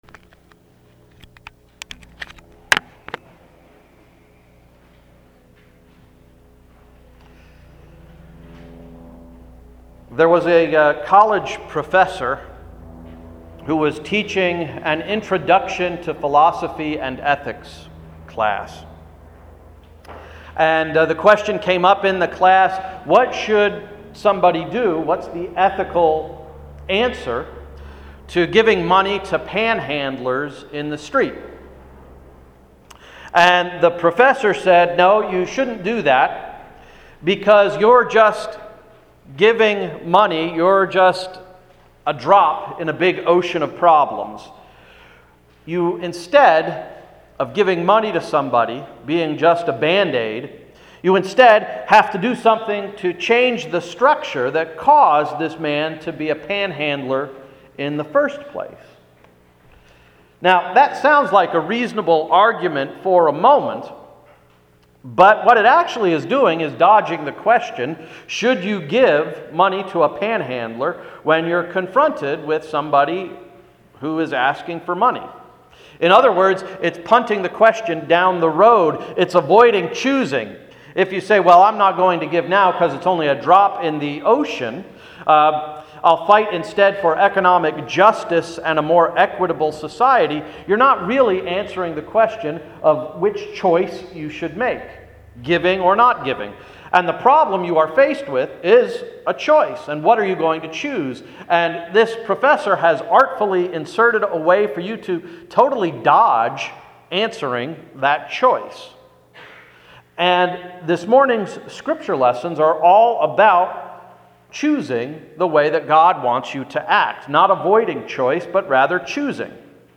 Sermon of September 5, 2010–“Choose the Right Path!”
To hear Sunday’s sermon, please use the link below: